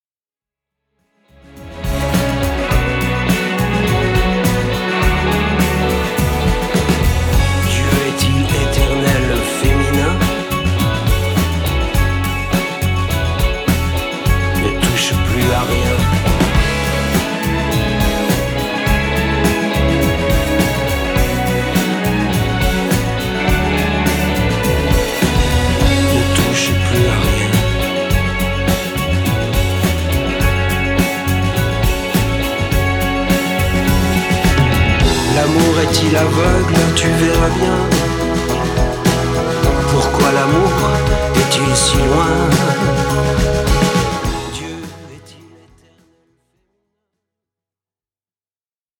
Sa voix qui chante et déclame nous interpelle.